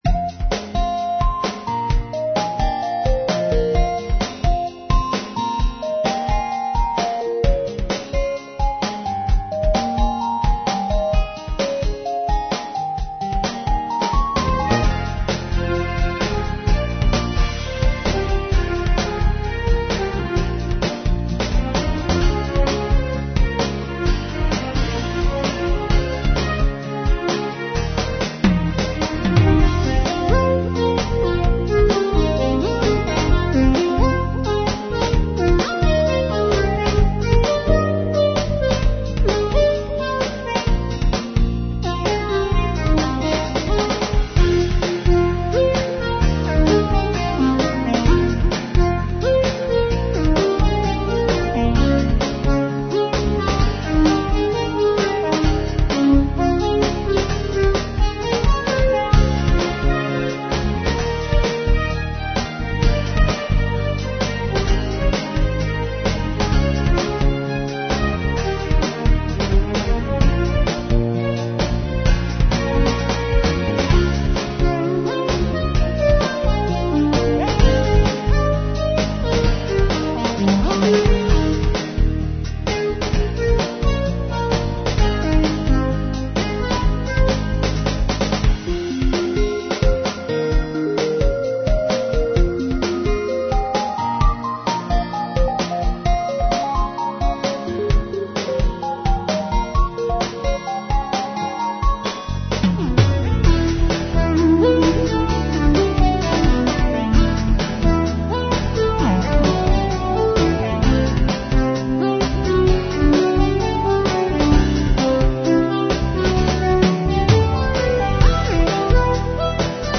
Dramatic Soundtrack music in style of Pop-Rock